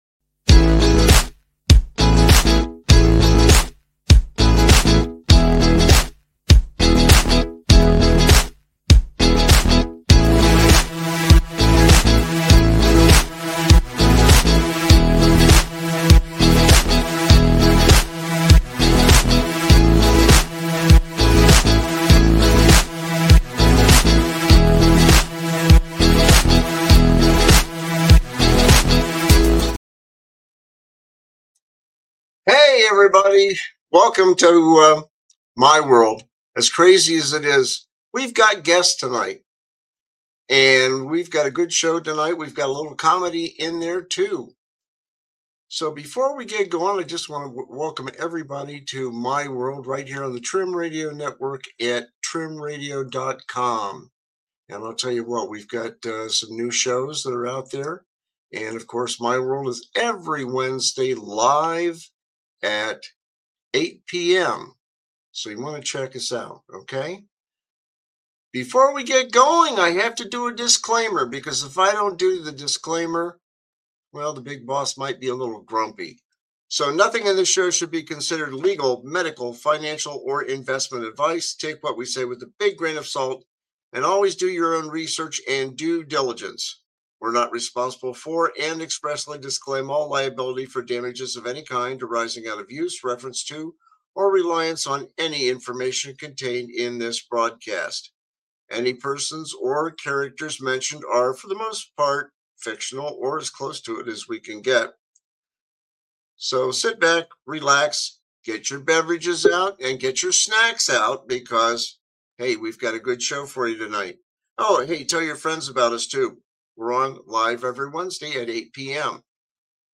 The panel focuses heavily on the controversy surrounding Non-Disclosure Agreements (NDAs), arguing that the practice of keeping financial and site details confidential should be challenged by local governments seeking full disclosure.